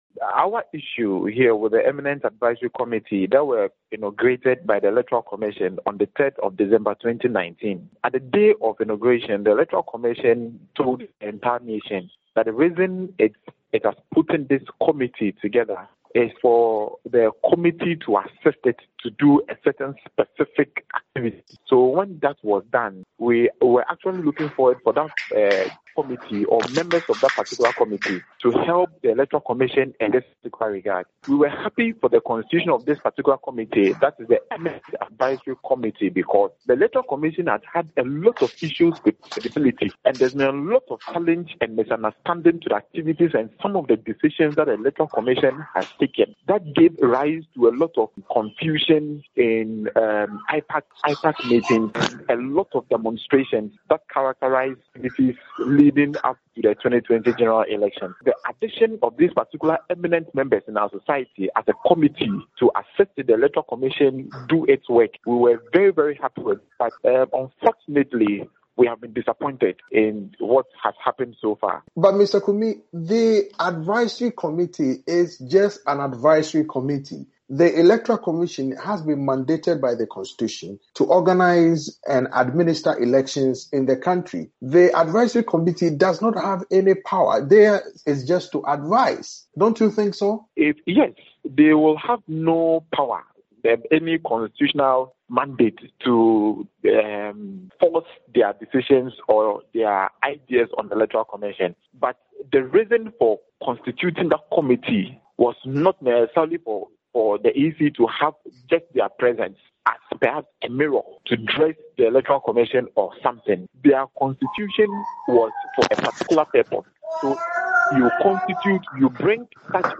A Ghanaian civil society organization is seeking the resignation of members of a group advising the electoral commission. For Nightline Africa